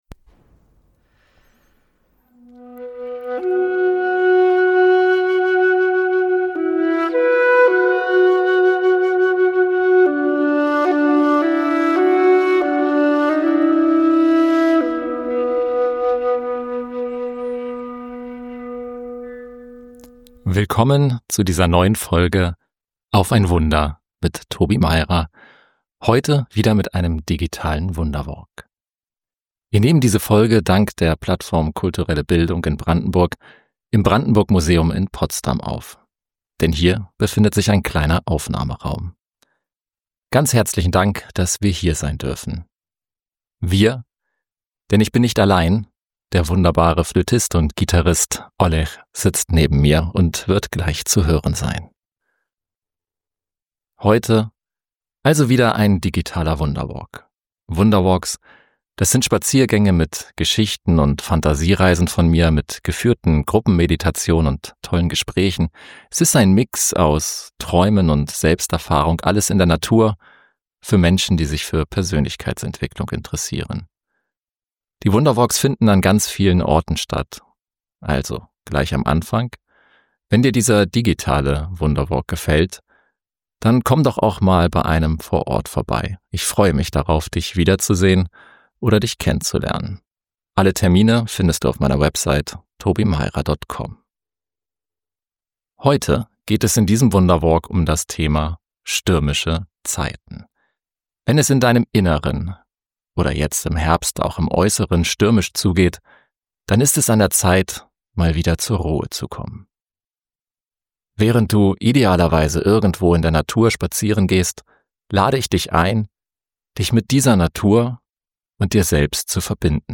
Aufgenommen wurde diese Folge im Brandenburg Museum in Potsdam.
Flöte